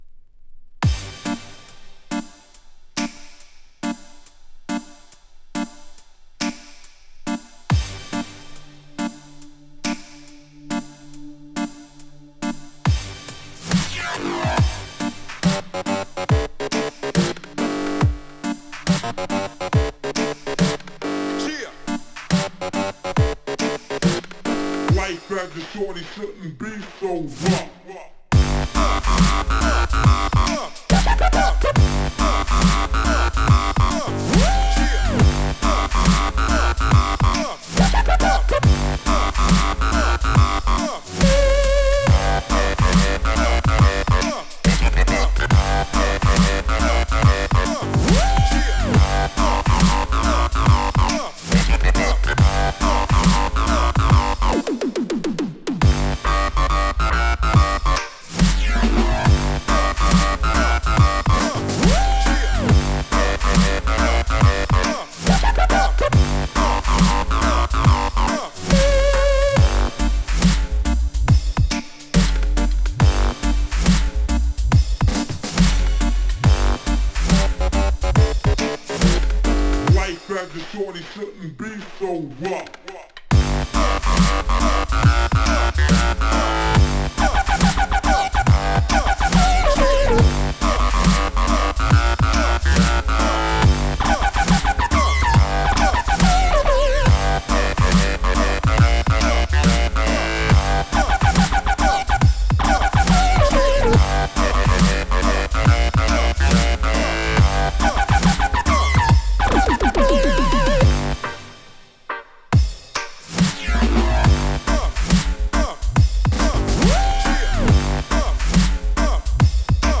2. > TECHNO/HOUSE
3. > BASS / DUB STEP / DRUM N' BASS